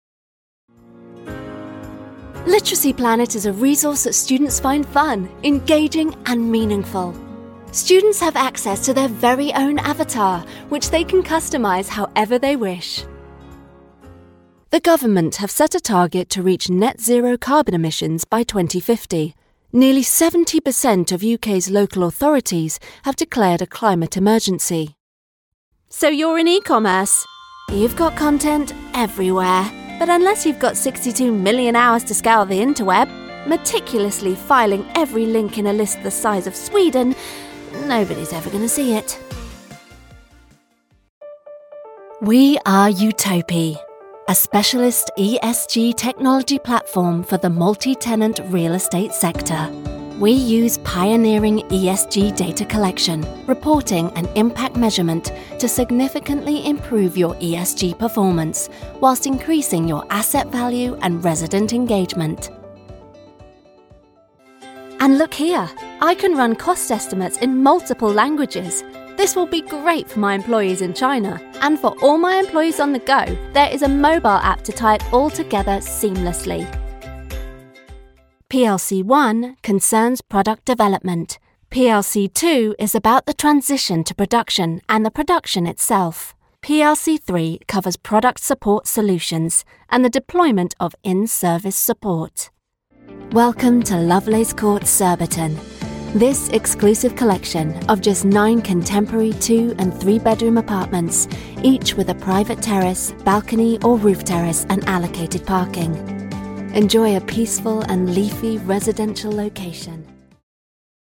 Corporate Showreel
Female
Neutral British
Estuary English
Bright
Friendly
Warm
Youthful